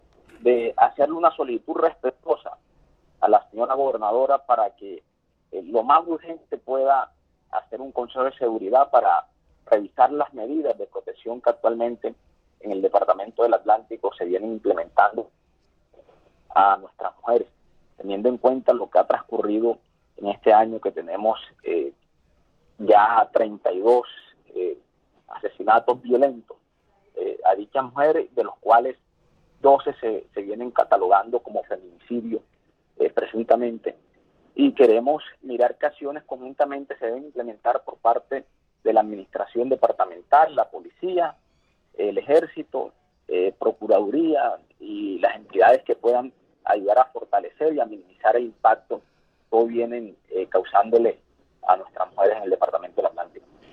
VOZ-DIPUTADO-GERSEL-TEMA-FEMINICIDIO.mp3